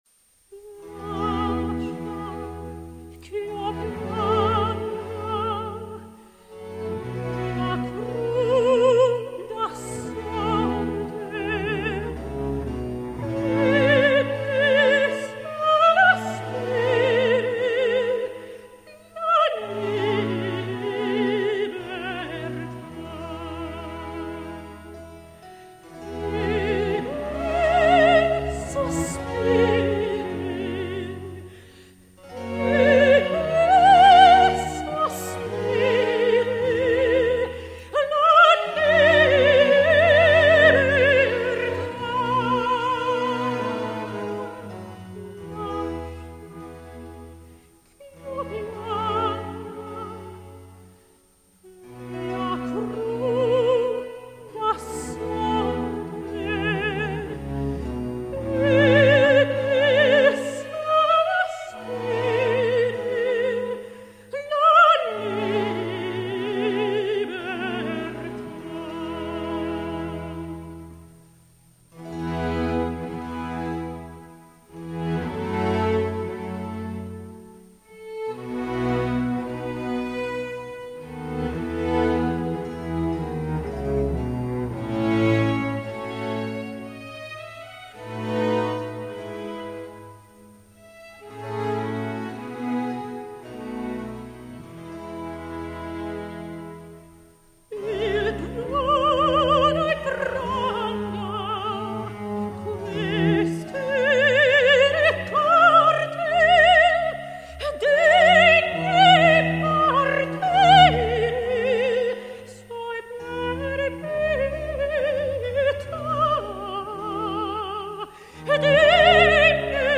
Género: Blues.